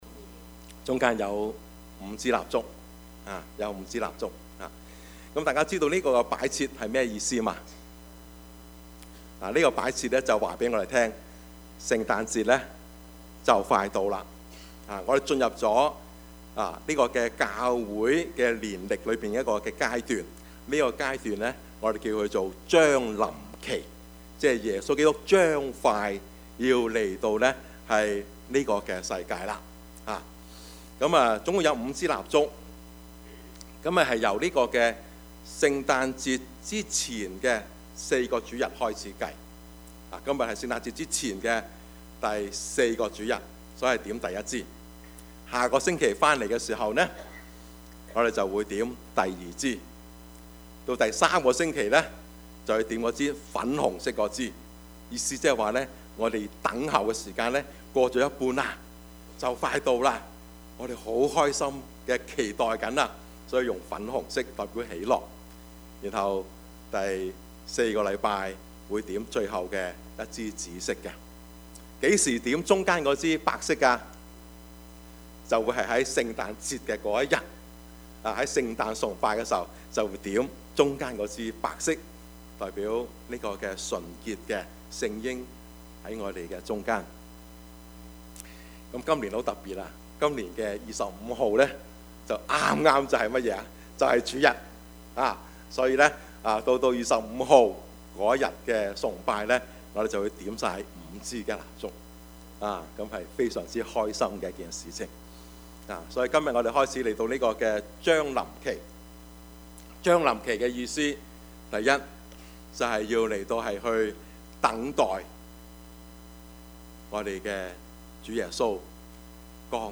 Service Type: 主日崇拜
Topics: 主日證道 « 施與受的喜樂 主已經近了 »